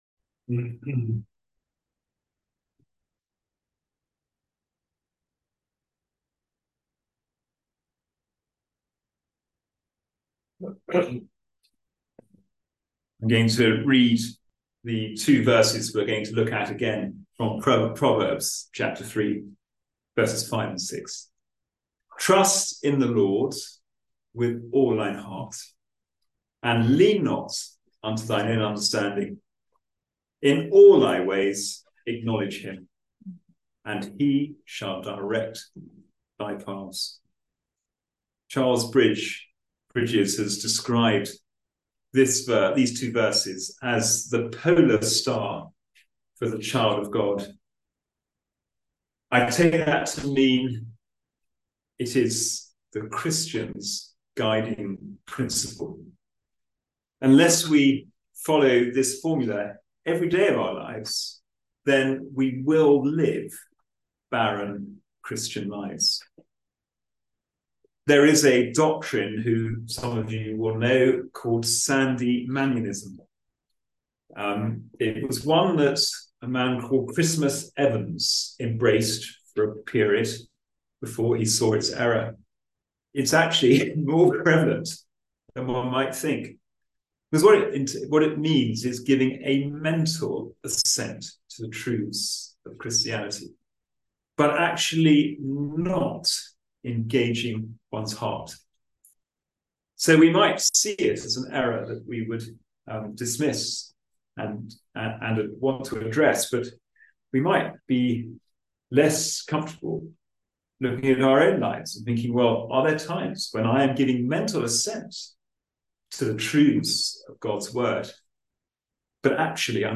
Passage: Proverbs 3:5-6 Service Type: Sunday Evening Service The Polar Star of the Christian Life « Sunday Morning